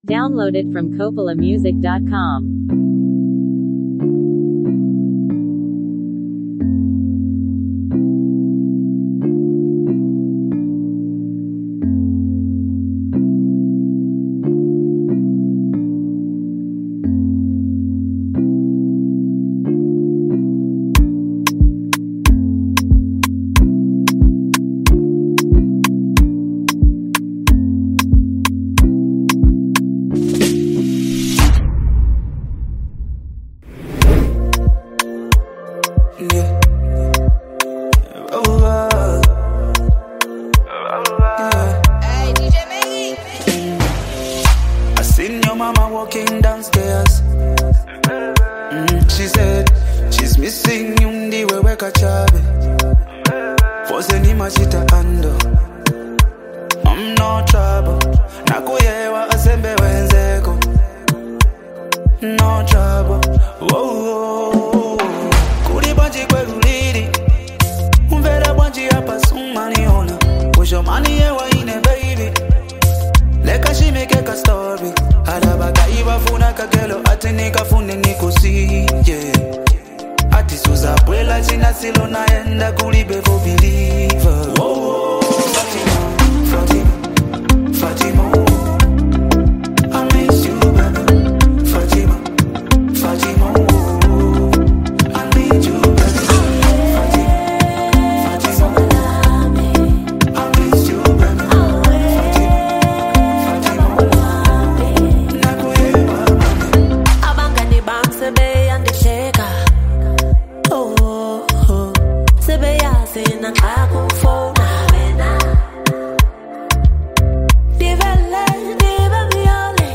smooth vocals
Backed by a gentle melody and rich production